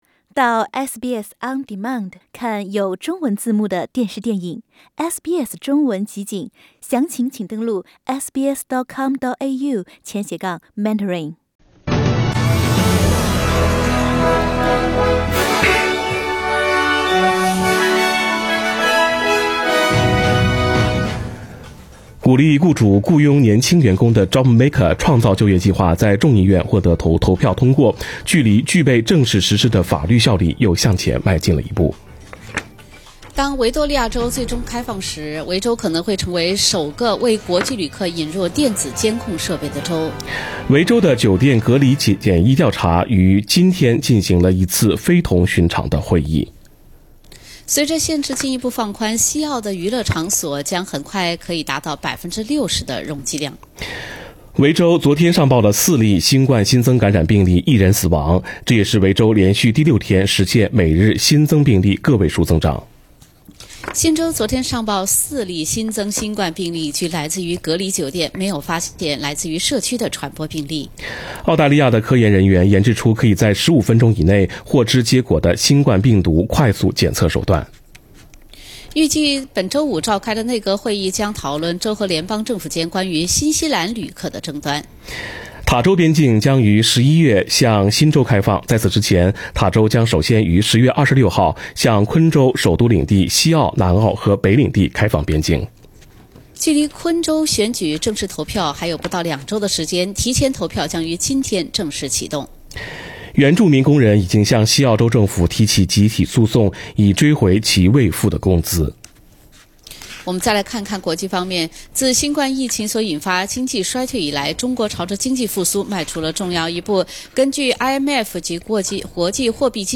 SBS Chinese Morning News Source: Shutterstock